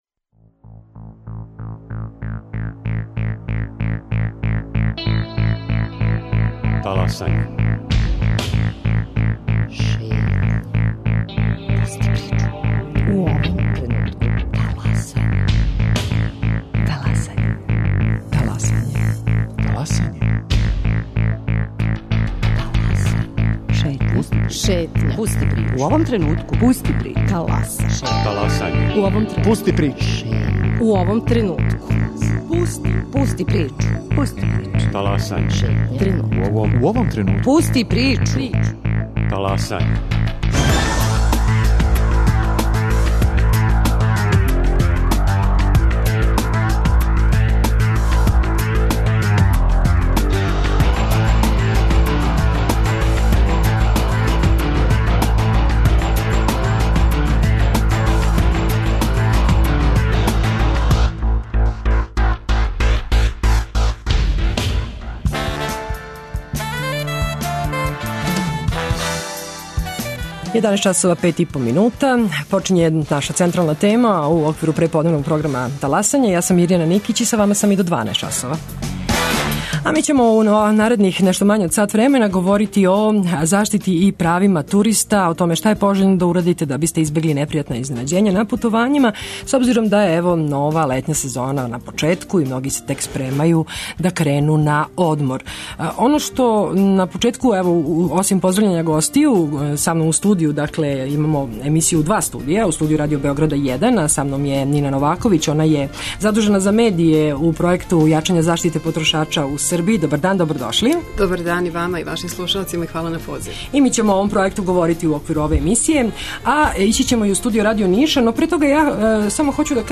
Какве су обавезе туристичких агенција и која су права путника? Гости Таласања су из удружења која се баве заштитом корисника туристичких услуга и јачањем права потрошача.